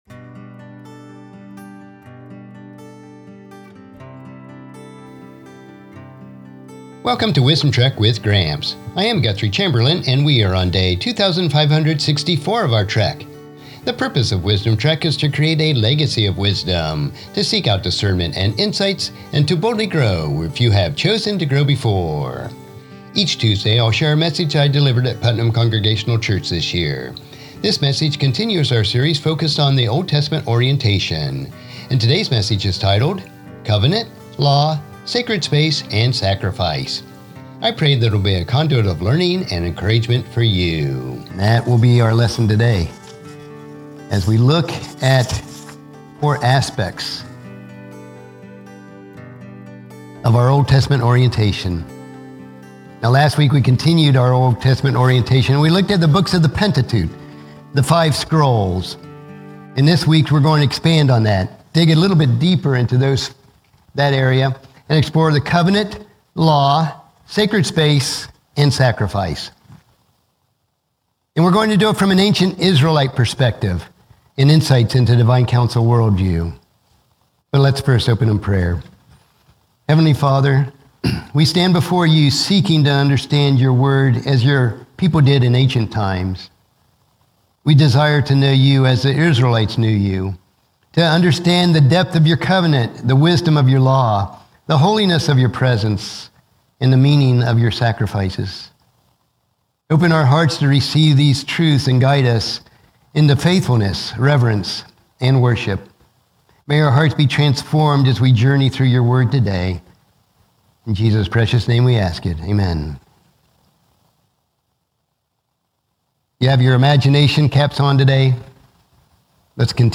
Sermon Series: Old Testament Orientation Message 5: Covenant, Law, Sacred Space, Sacrifice